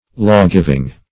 Lawgiving \Law"giv`ing\